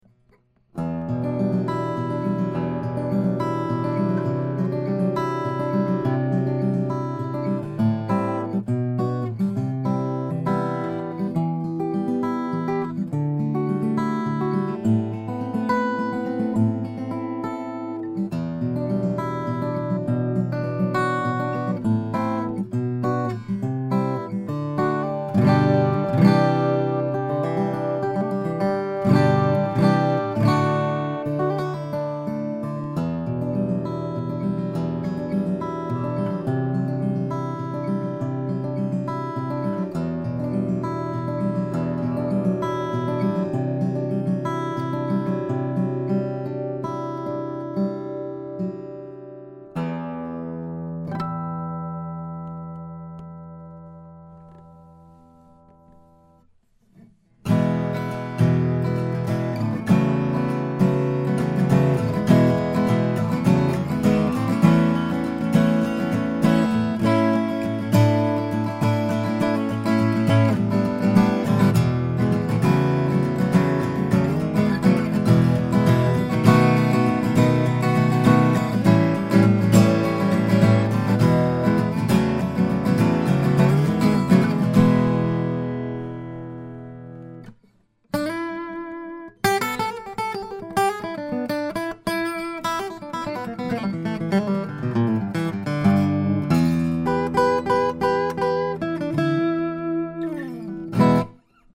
The voice is focused and dense.